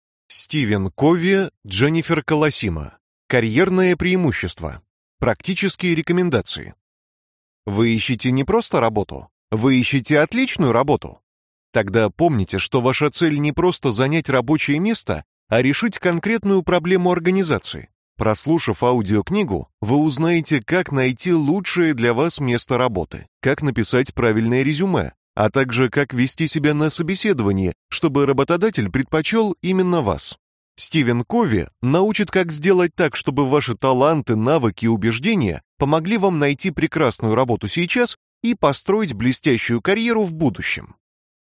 Аудиокнига Карьерное преимущество: Практические рекомендации | Библиотека аудиокниг